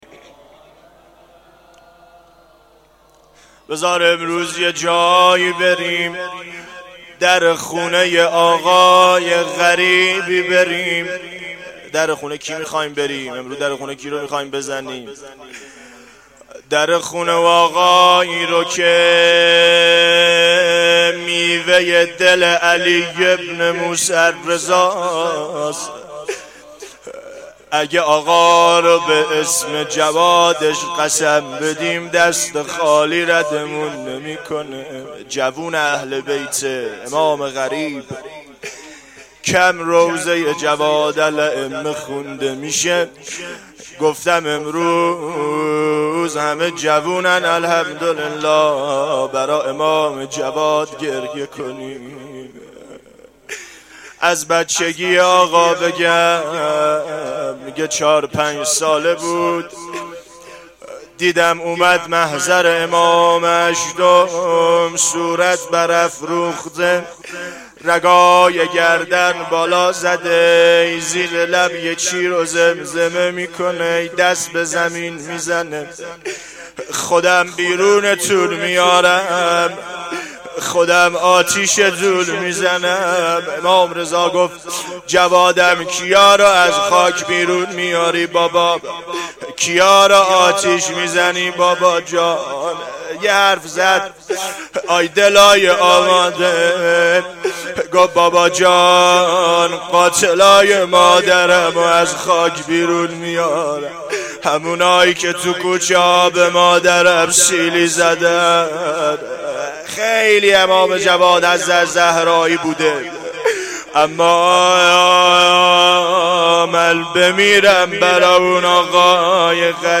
روضه6
روضه-3.mp3